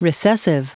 Prononciation du mot recessive en anglais (fichier audio)
Prononciation du mot : recessive